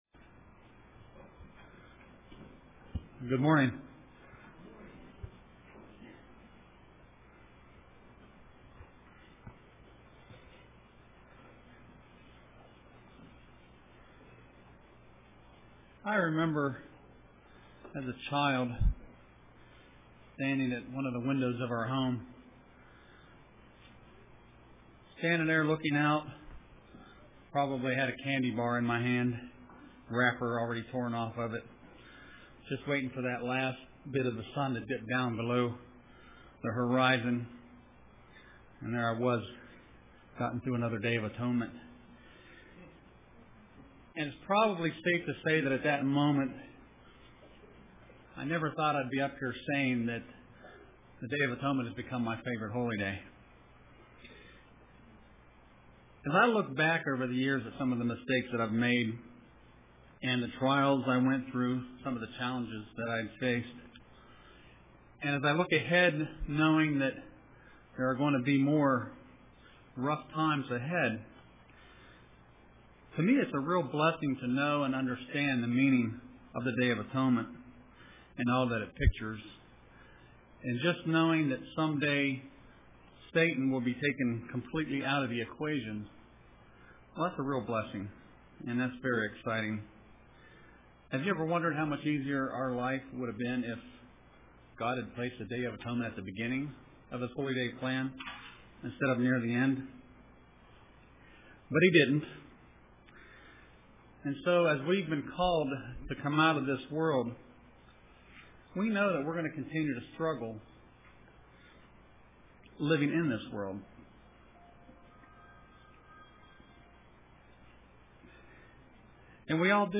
Print Comfort in Trials UCG Sermon